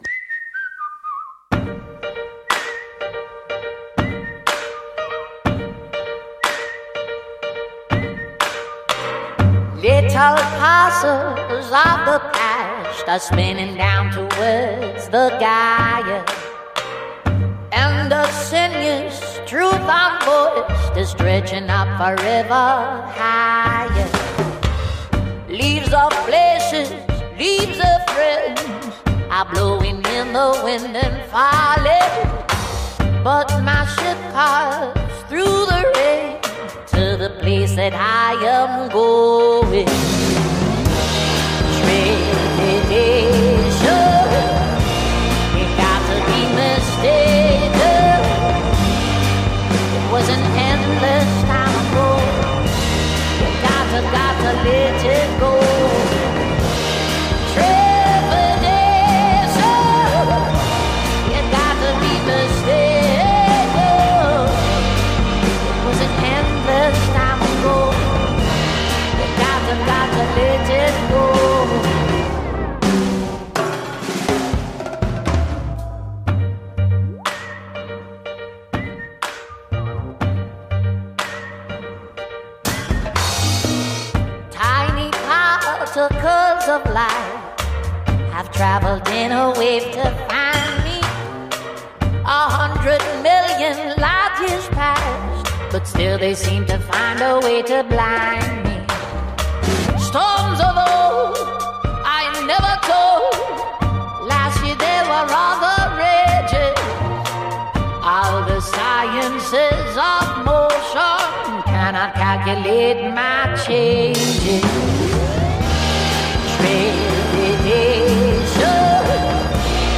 Entretien
en direct des Rencontres de Chabeuil